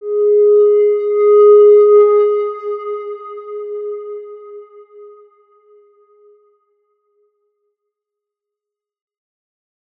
X_Windwistle-G#3-pp.wav